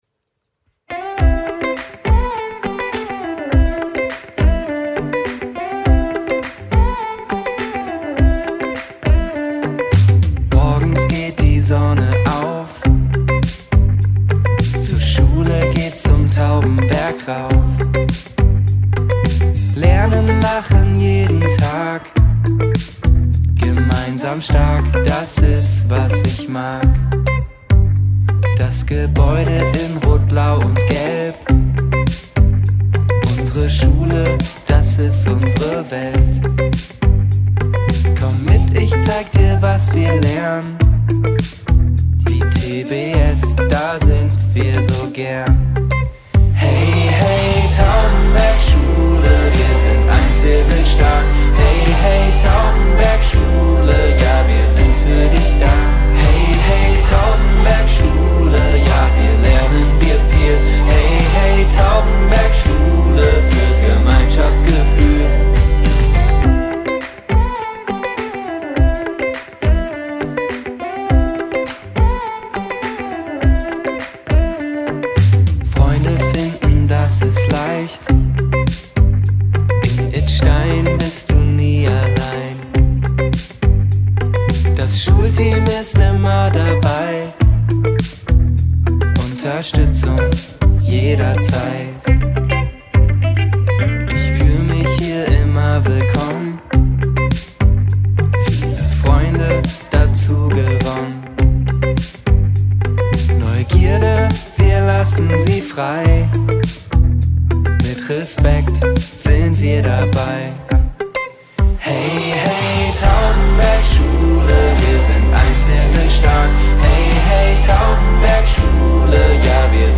Das ist unser Schulsong!
SONG